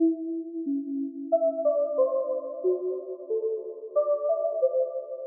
Tag: 91 bpm Hip Hop Loops Synth Loops 908.83 KB wav Key : Unknown